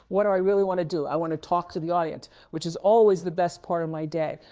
Guess which part is synthesized!